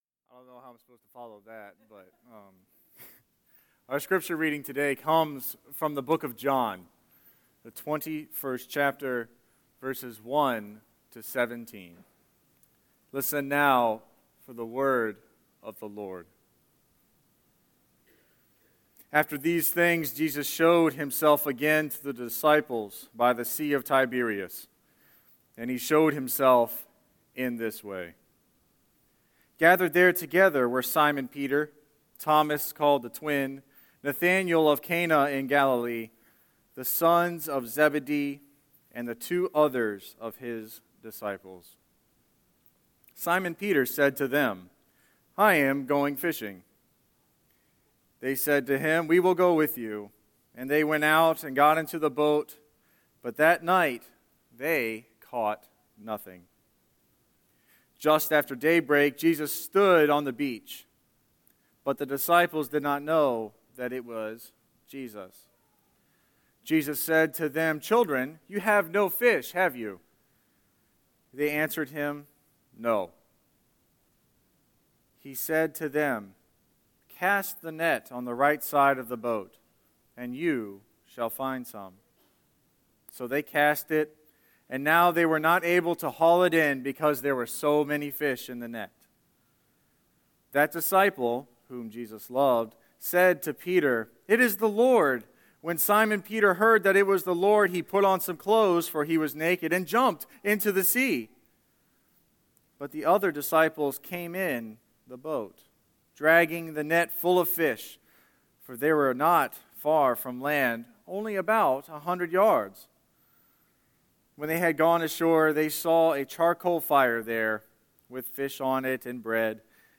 Listen to this week’s Scripture and Sermon
04-10-Scripture-and-Sermon.mp3